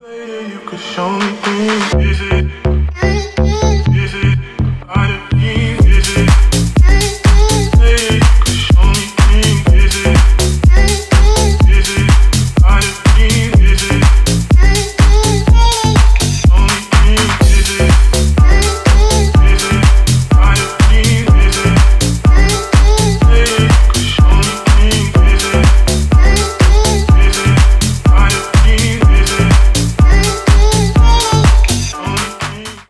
Ремикс # Танцевальные
клубные